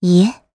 Xerah-Vox-Deny_jp.wav